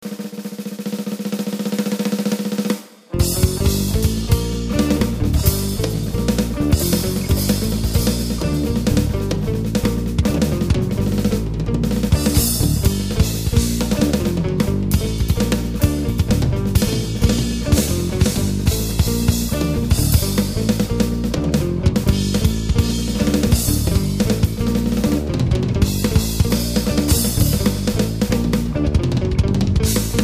basso elettrico
chitarra